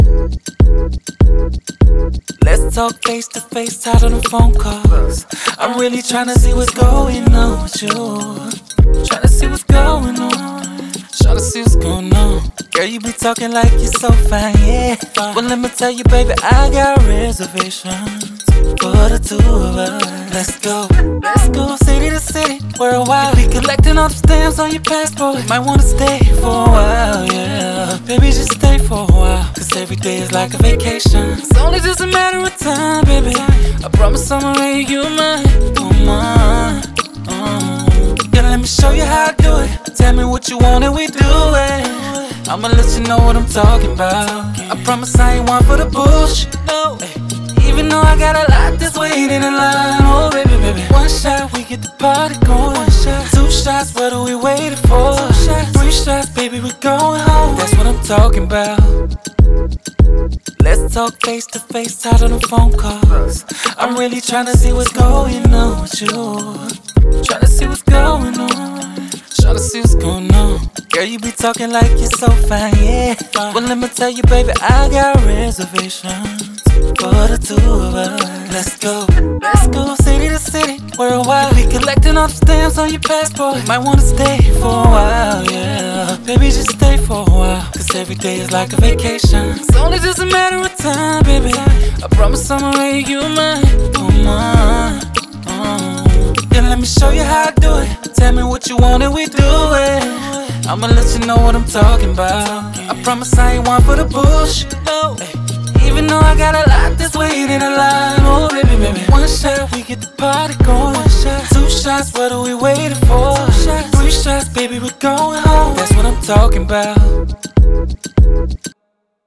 Hip Hop, R&B
Ab Minor